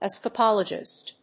音節es・ca・pól・o・gist 発音記号・読み方
/‐dʒɪst(米国英語), ˈɛs.kəˌpɒl.ə.dʒɪst(英国英語)/